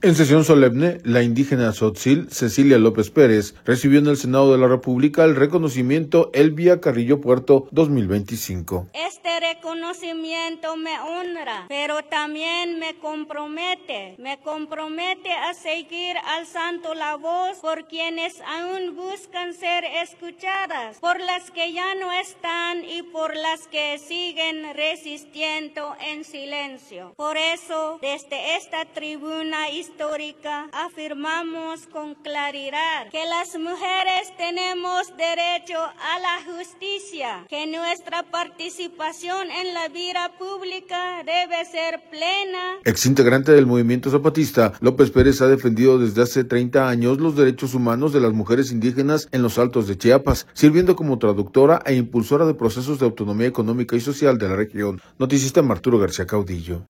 En sesión solemne